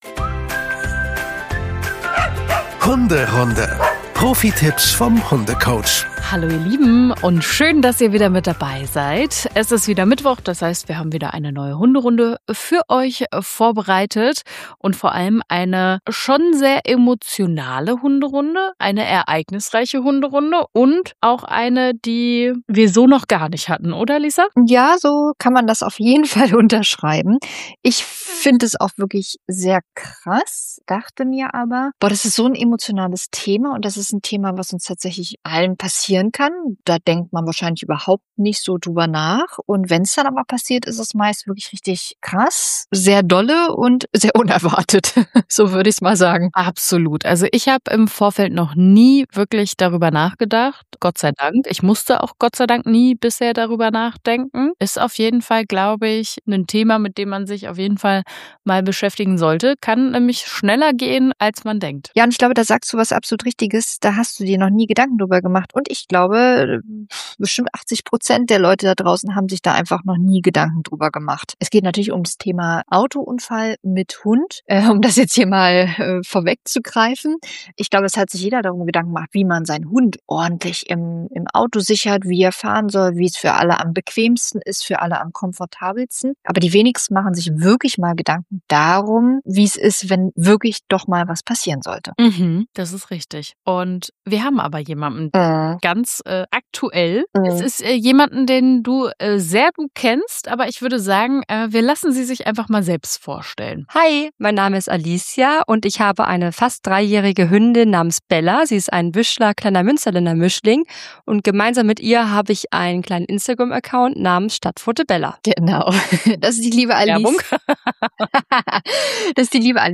Mithilfe ihrer persönlichen Sprachnachrichten erfahrt ihr Schritt für Schritt, was passiert ist, wie Bella reagiert hat und welche Sorgen und Herausforderungen danach auf alle Beteiligten zukamen.